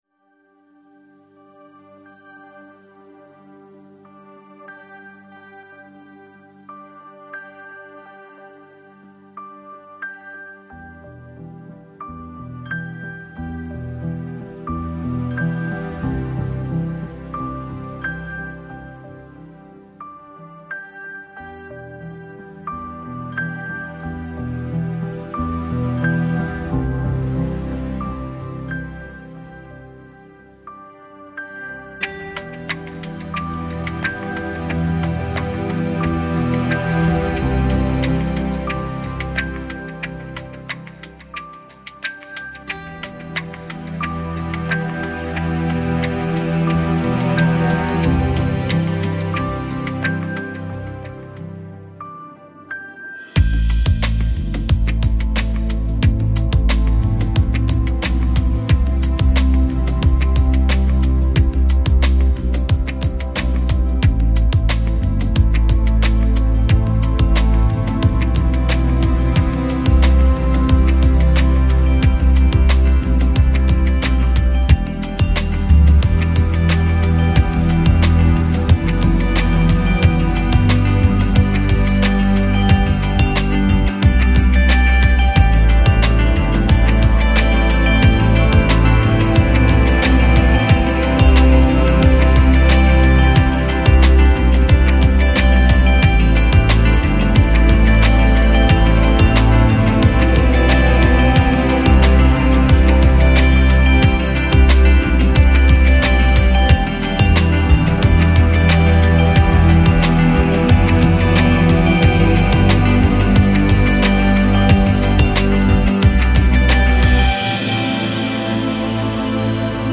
Downtempo, Chillout, Electronic